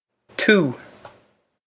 tú too
This is an approximate phonetic pronunciation of the phrase.